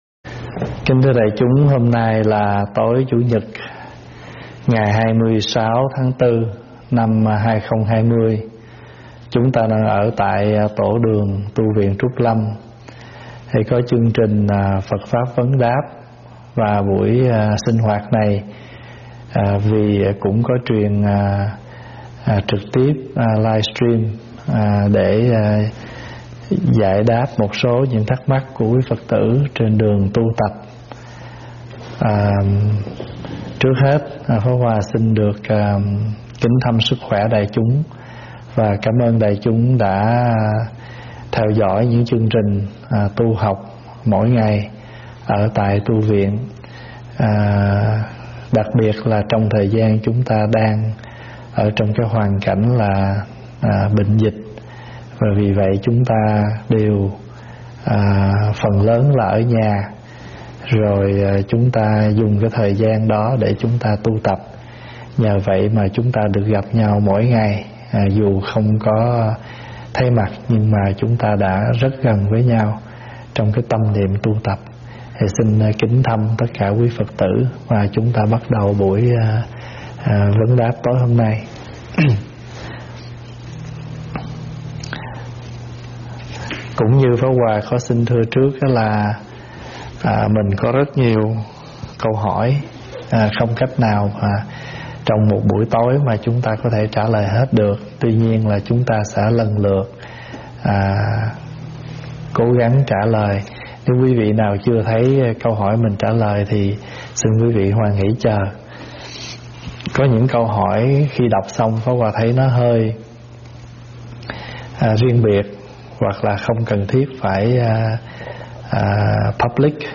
giảng Livestream tại tv Trúc Lâm